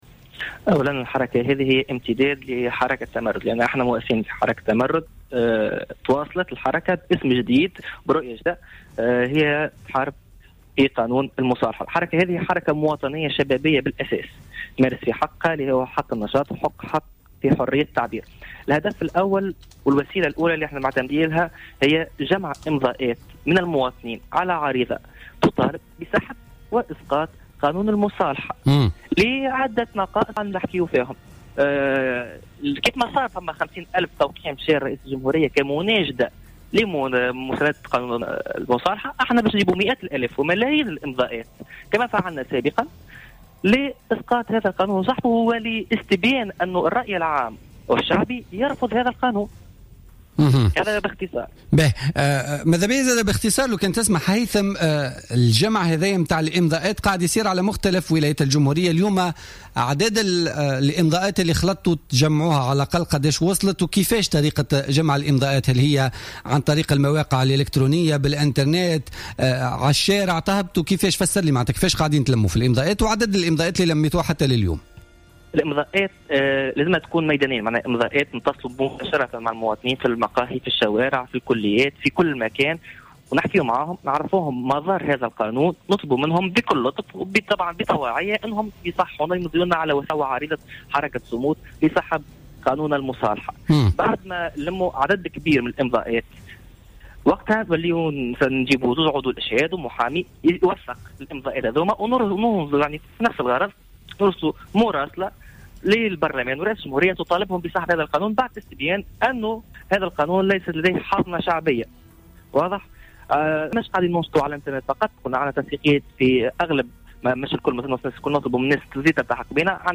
مداخلة له اليوم في برنامج "بوليتيكا"